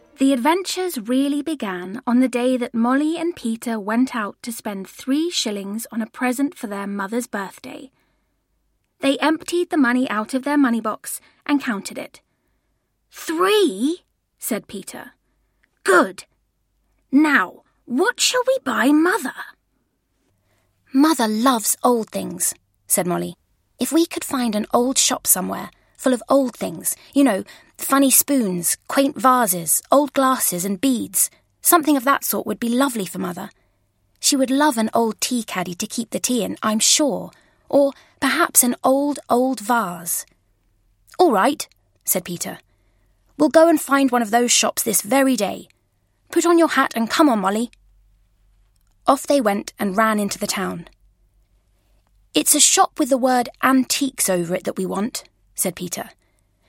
• Feature-length story